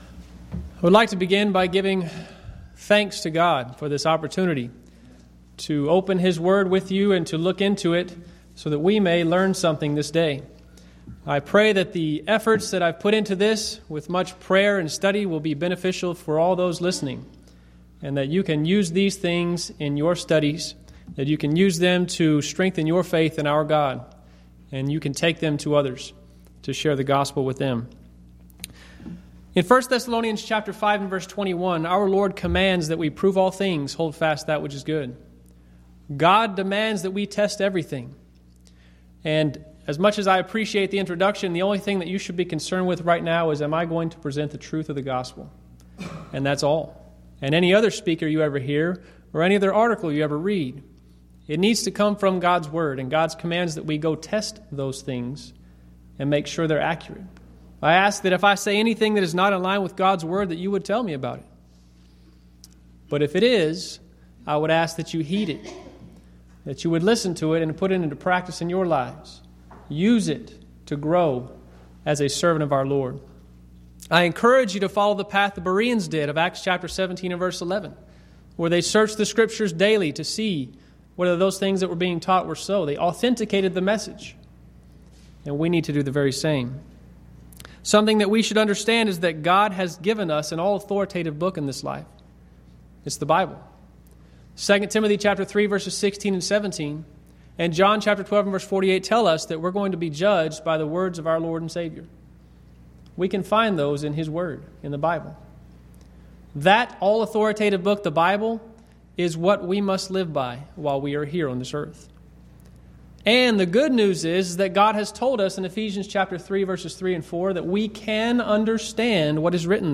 Series: Schertz Lectureship Event: 10th Annual Schertz Lectures